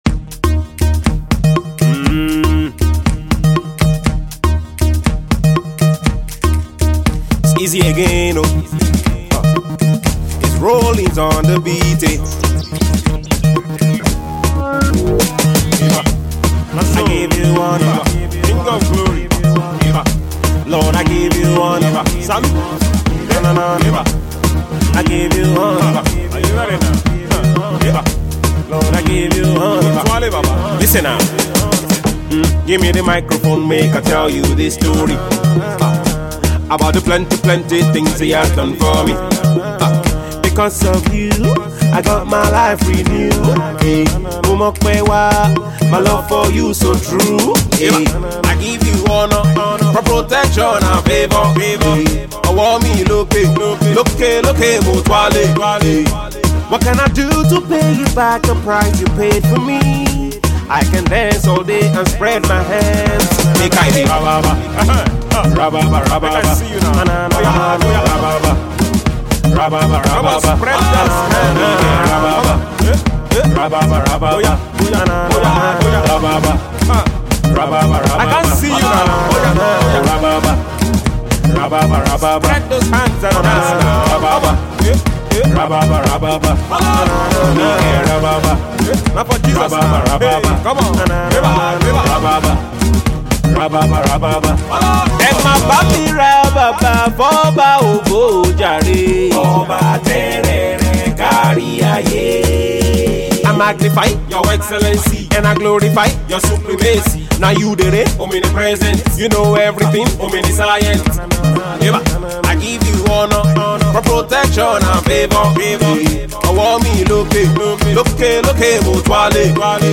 dance track
catchy afro tune